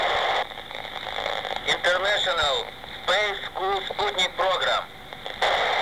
Recorders was done by me on 15/11/98 23:04 utc with FT 203R hand-RTX , antenna Jpole homemade.
Message # 2 (Telemetry temperature tone)